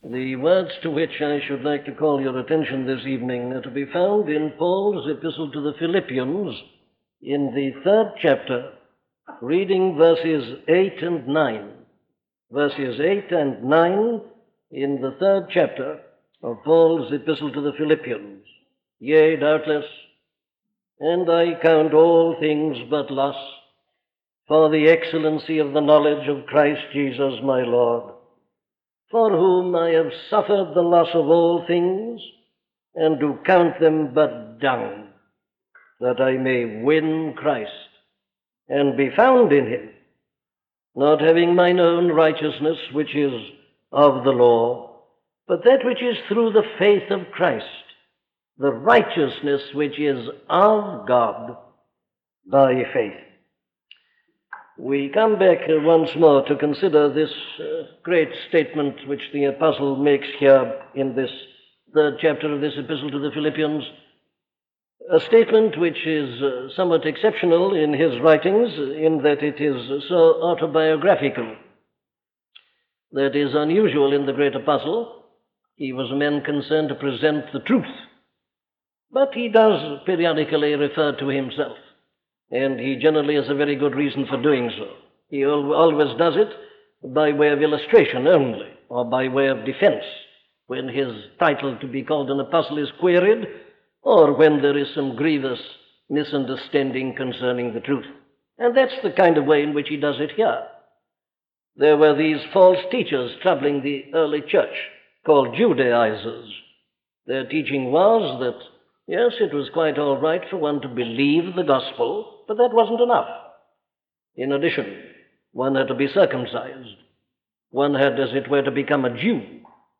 An audio library of the sermons of Dr. Martyn Lloyd-Jones.